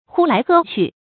呼来喝去 hū lái hè qù
呼来喝去发音